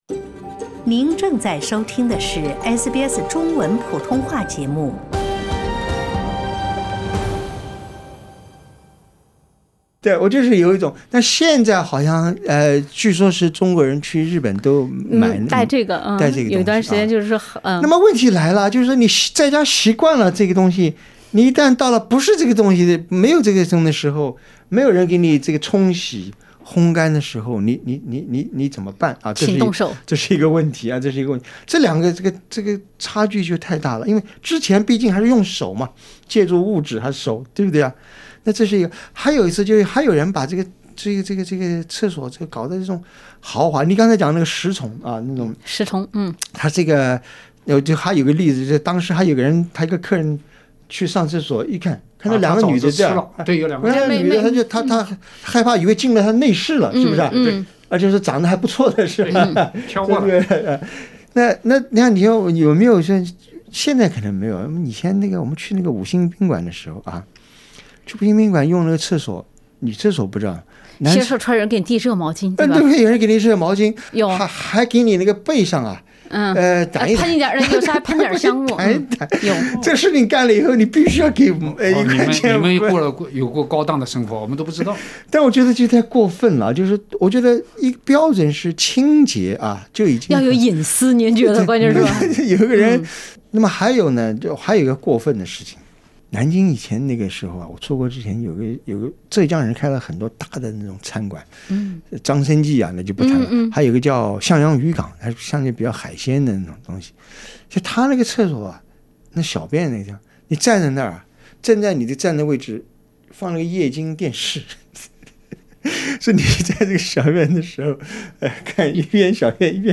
10:51 Source: Pixabay SBS 普通话电台 View Podcast Series Follow and Subscribe Apple Podcasts YouTube Spotify Download (19.88MB) Download the SBS Audio app Available on iOS and Android 厕所革命早就不是新鲜话题。